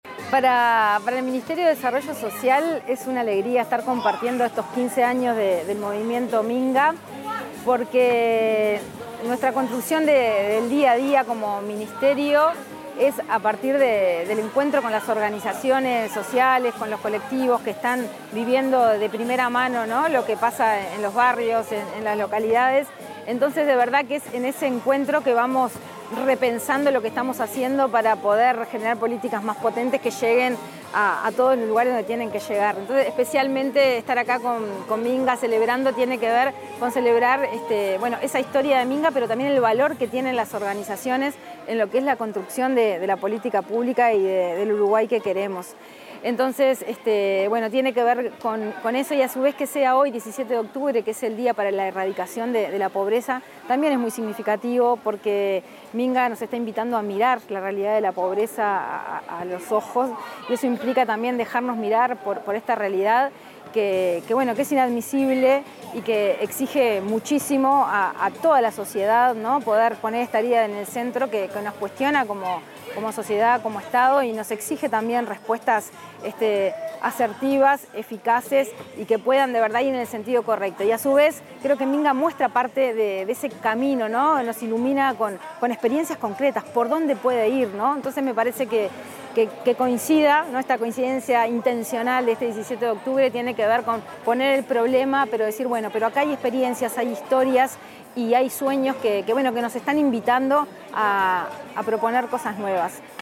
Declaraciones de la directora nacional de Gestión Territorial, Mercedes Clara
Declaraciones de la directora nacional de Gestión Territorial, Mercedes Clara 18/10/2025 Compartir Facebook X Copiar enlace WhatsApp LinkedIn La directora nacional de Gestión Territorial del Ministerio de Desarrollo Social, Mercedes Clara, expuso en la celebración de los 15 años del Movimiento Minga, en el marco del Día Internacional para la Erradicación de la Pobreza.